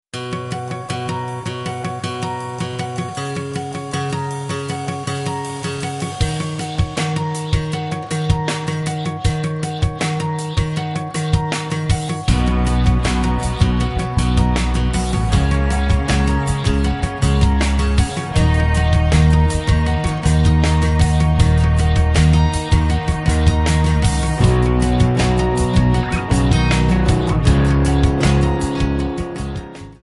Backing track Karaoke
Rock, 2000s